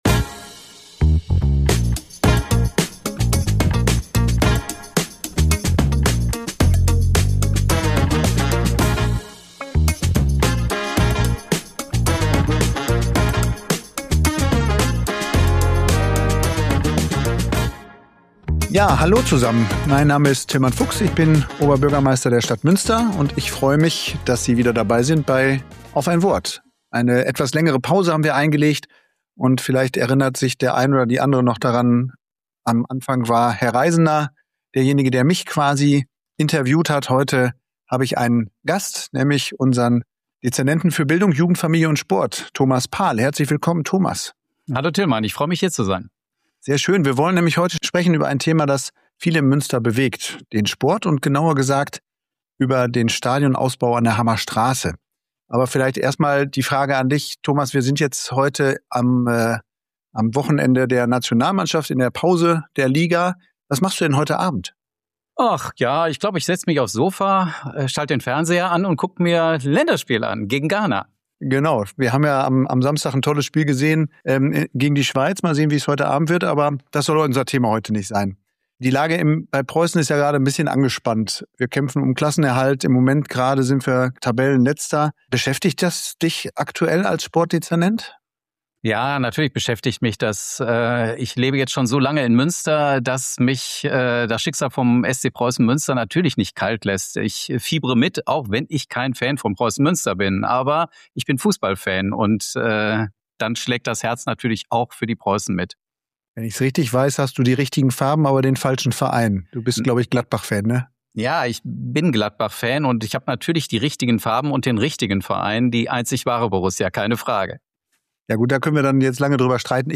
Oberbürgermeister Tilman Fuchs spricht mit Thomas Paal (Dezernent für Bildung, Jugend, Familie und Sport) über den Ausbau des LVM-Preußenstadions an der Hammer Straße – die Bauarbeiten liegen weiterhin voll im Zeitplan.